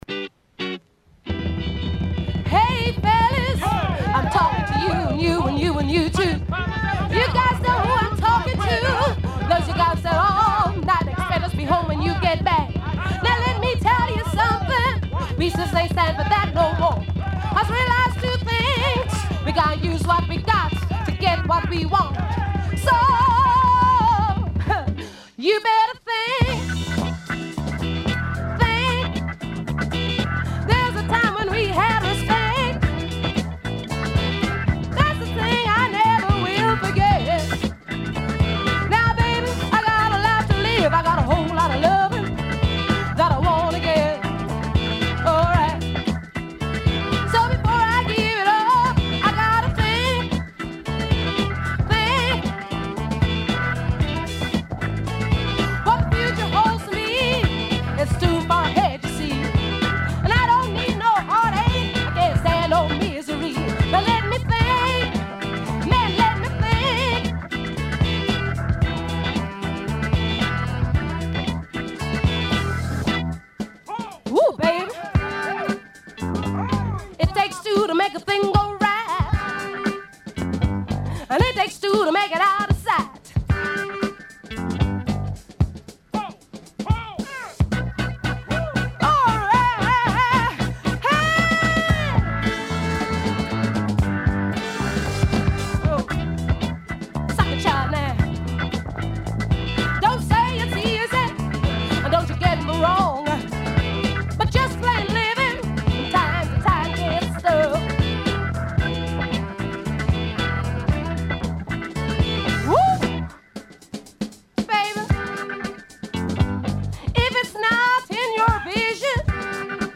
Very funky.